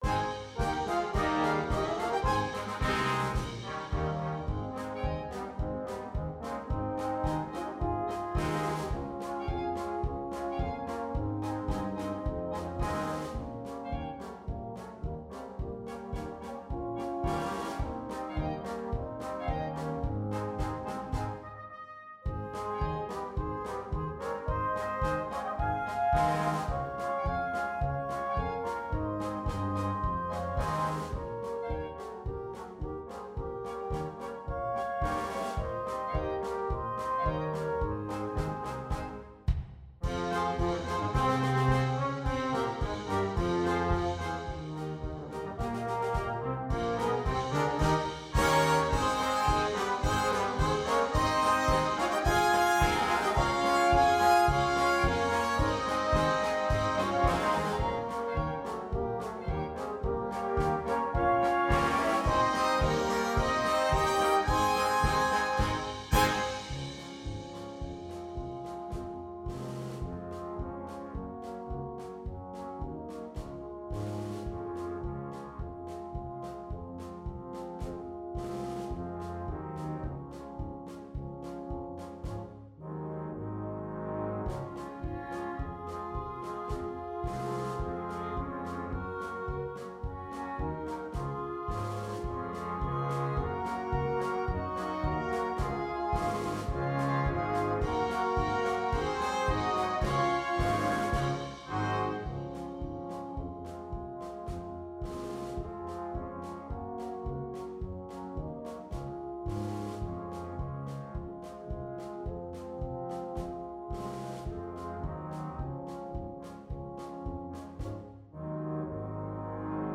Full Band
without solo instrument